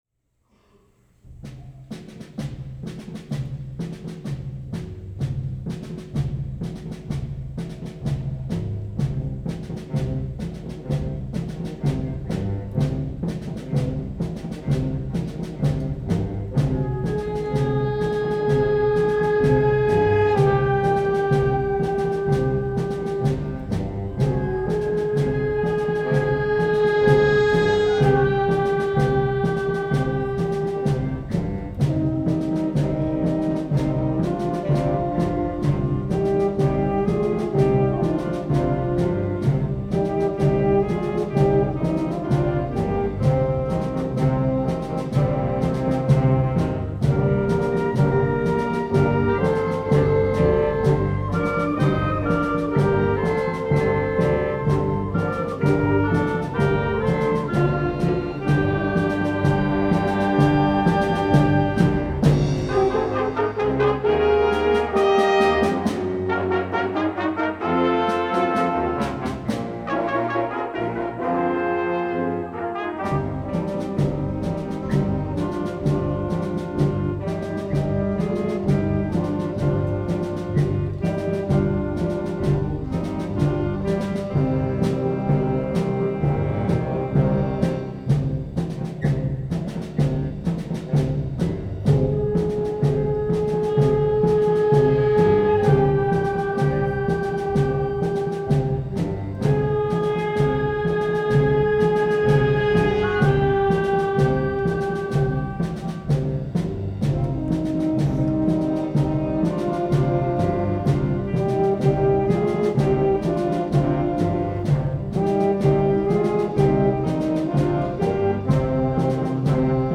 Alexander Bands — Winter Concert 2016
Alexander Symphonic Band